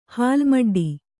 ♪ hālmaḍḍi